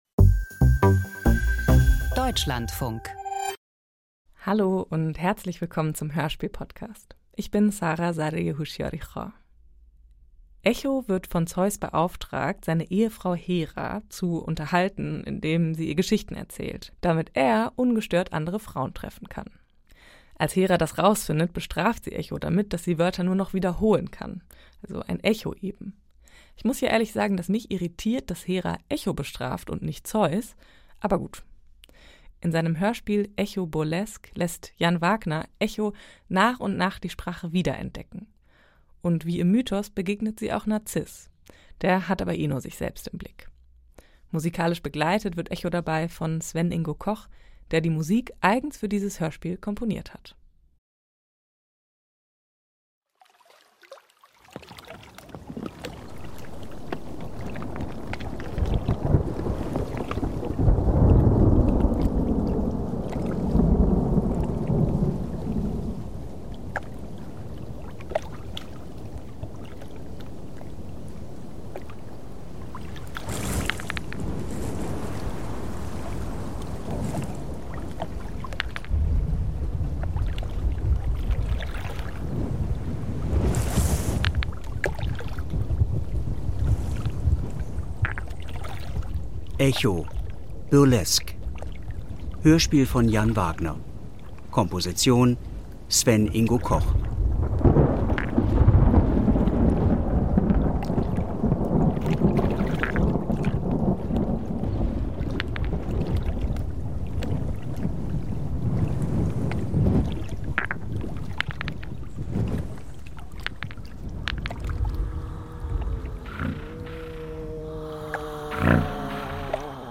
Hörspiel von Jan Wagner - Echo.
• Literatur • Die Nymphe Echo wurde zum Nachplappern, zum Stummsein verdammt, verzehrte sich und wurde zu nichts als Kieseln und Klang. Wo sonst als in einem Hörspiel sollten ihr – vierstimmig, vielschichtig, mit Witz und Bitterkeit – Leib, Leben und Lieben zurückerstattet werden?